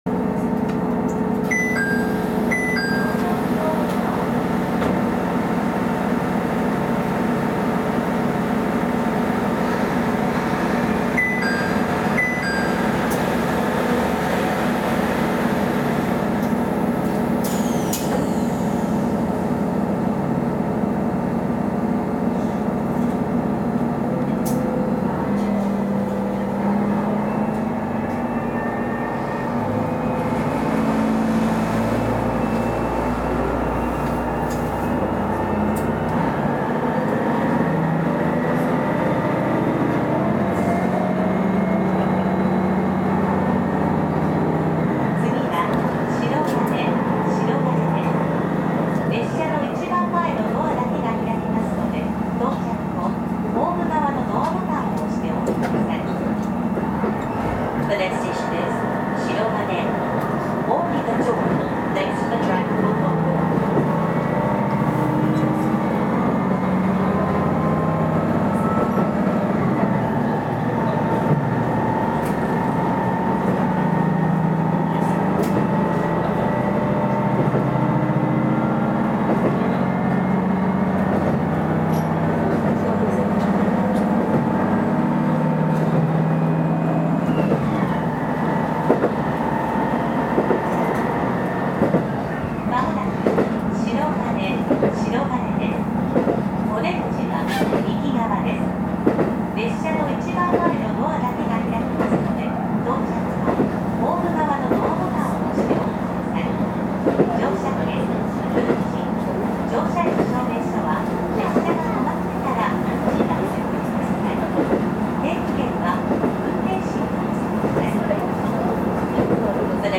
走行音
録音区間：上総清川～祇園(お持ち帰り)